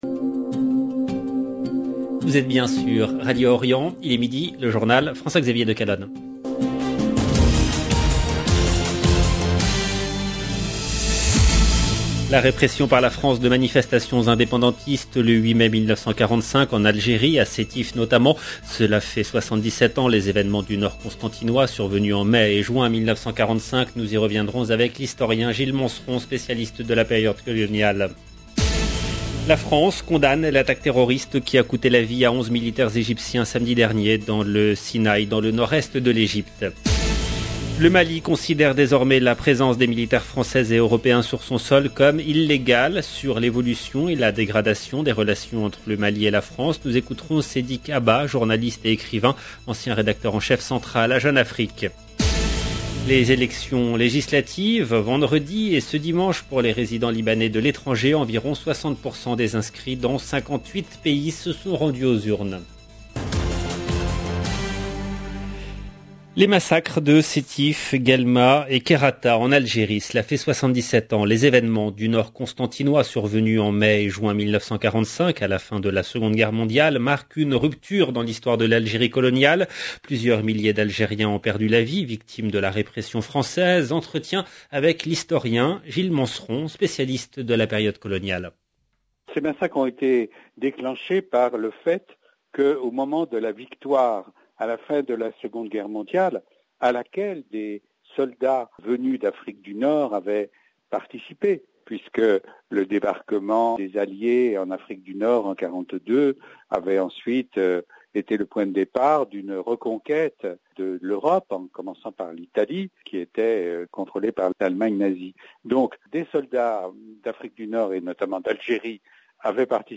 LE JOURNAL EN LANGUE FRANCAISE DE MIDI DU 9/05/22